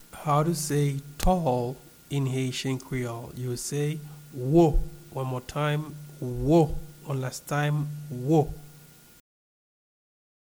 Pronunciation and Transcript:
Tall-in-Haitian-Creole-Wo-pronunciation.mp3